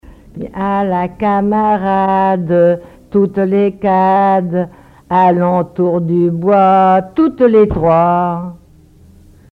formulette enfantine : amusette
Témoignages et chansons traditionnelles
Pièce musicale inédite